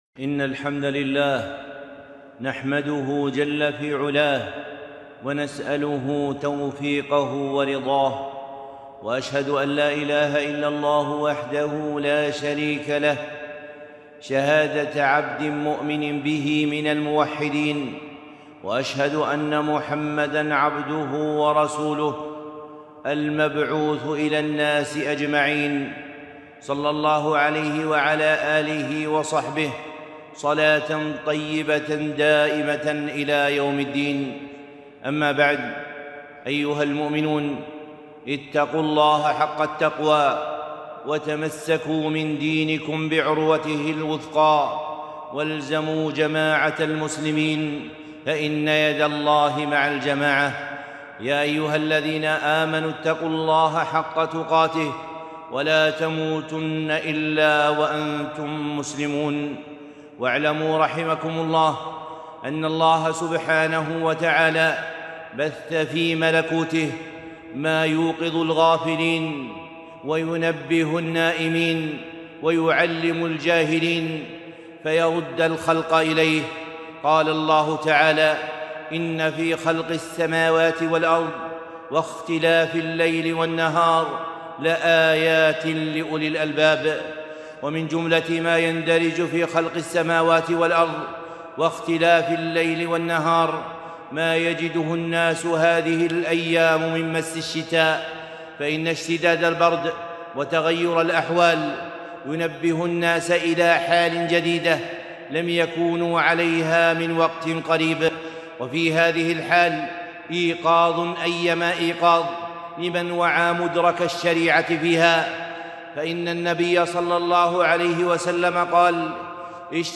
خطبة - النار في الشتاء 9-6-1442